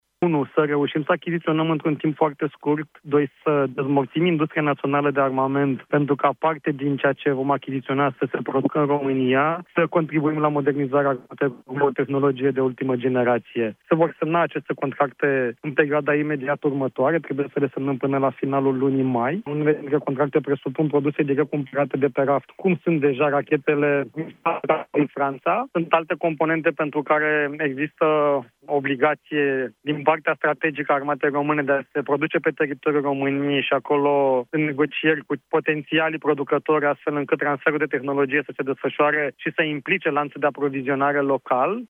O parte din echipamentele militare vor fi produse în România, a precizat la Europa FM ministrul Apărării, Radu Miruță.